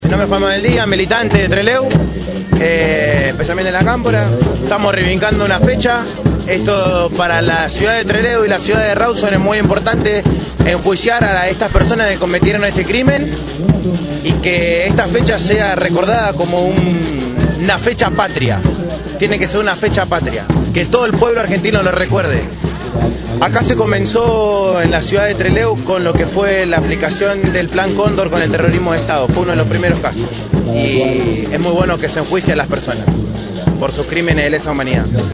La sentencia se conoció en el centro Cultural Municipal «José Hernández», de Rawson.
Los enviados especiales de Radio Gráfica recopilaron los testimonios de los mismos.